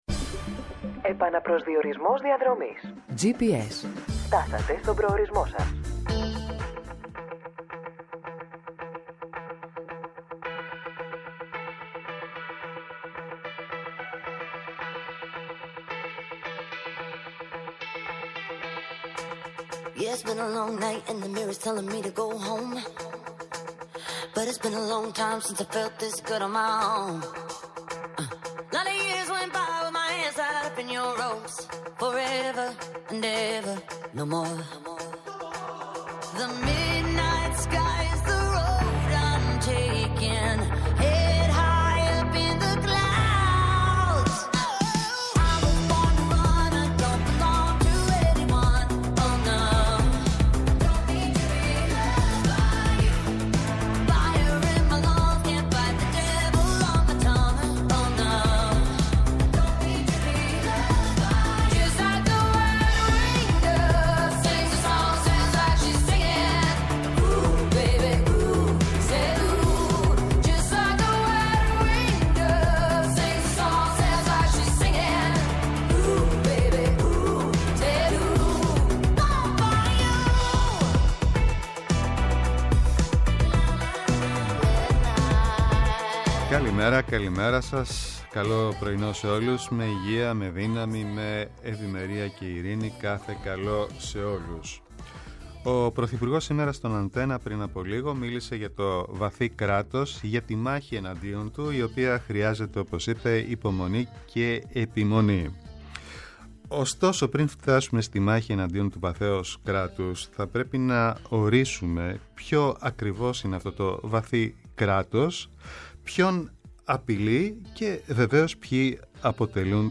-Ο Άγγελος Συρίγος, βουλευτής ΝΔ, καθηγητής Διεθνούς Δικαίου του Παντείου Πανεπιστημίου
πολιτικός αναλυτής και επικοινωνιολόγος
αποκαλυπτικές συνεντεύξεις και πλούσιο ρεπορτάζ